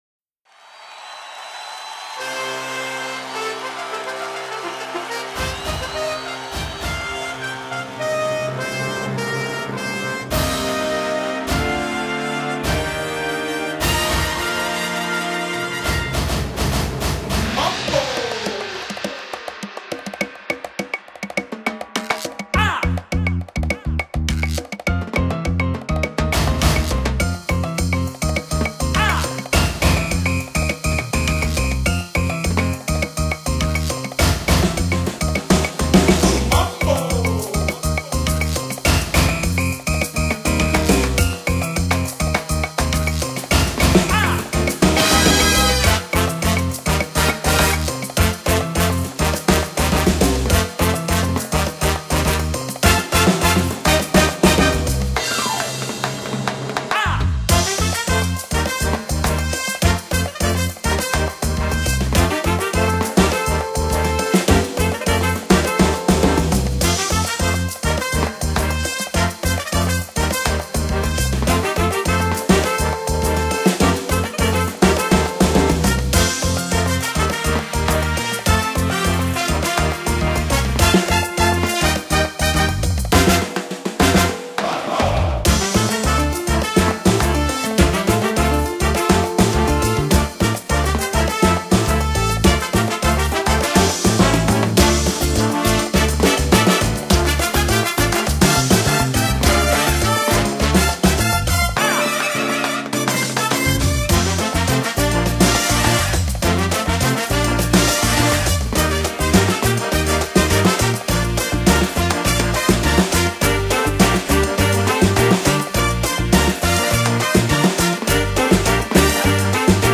Musica da Ballo
Mambo per ensemble di ottoni, sax e percussioni